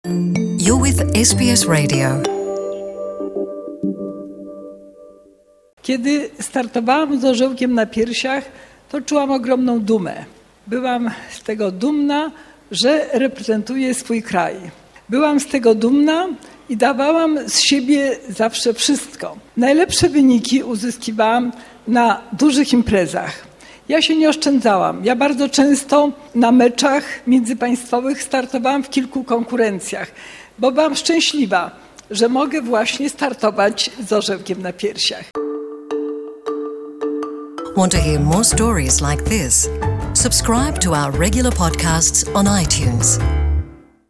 In January at the National Stadium, Irena Szewińska took part in the event with athletes and President Andrzej Dudaon on the occasion of the 100th anniversary of independence. She talked about the importance of representing Poland at international competitions: (sound - courtesy of IAR)